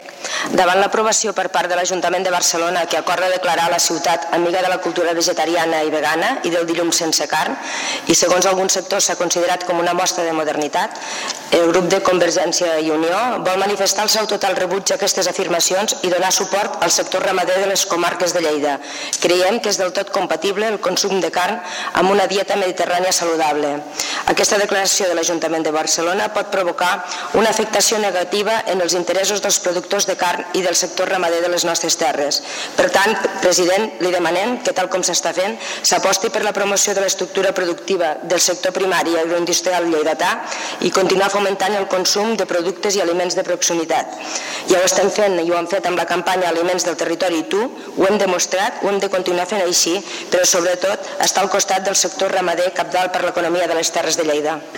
Rosa_Pujol_prec_defensa_ramaders.mp3